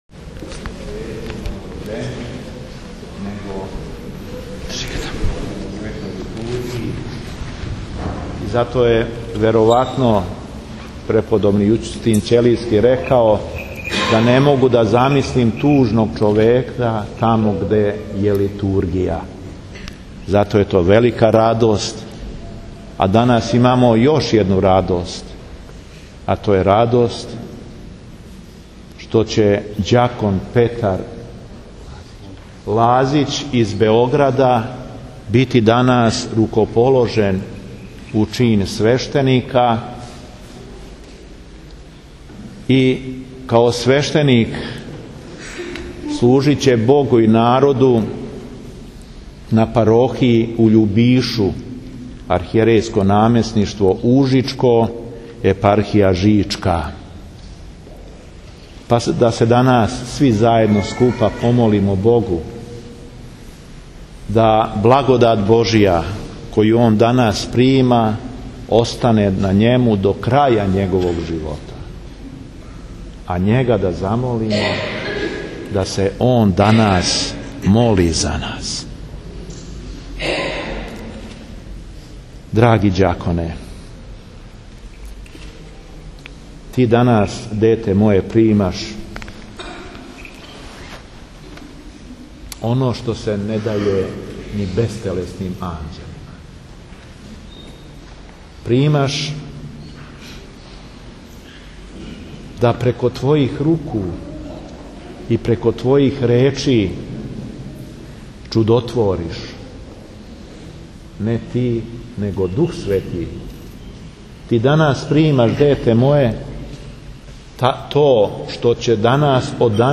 ХРАМОВНА СЛАВА НА АЕРОДРОМУ - Епархија Шумадијска
Беседа епископа шумадијског и администратора жичког Г. Јована
Након Јеванђеља, Преосвећени Владика се обратио окупљеним верницима беседом у којој је нагласио да је пут Светог Саве, пут вере у Васкрслог Христа и пут у васкрсење.